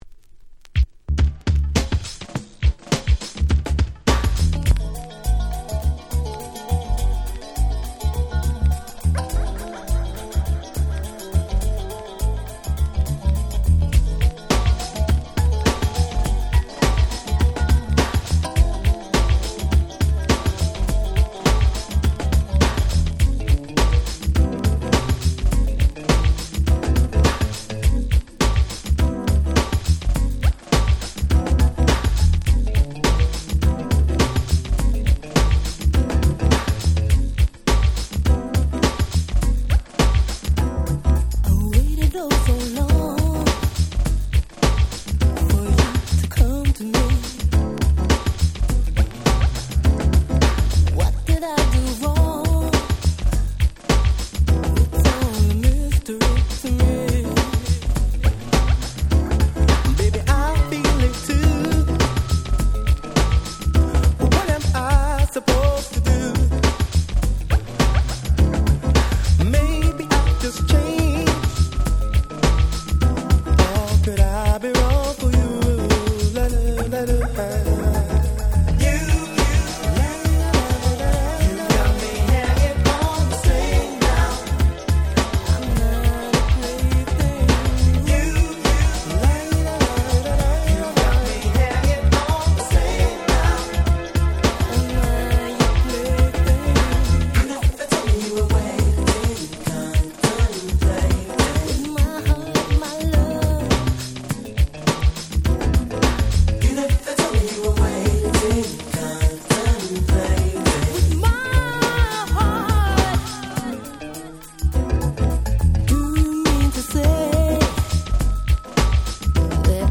最高のUK Soul / Disco Boogieです。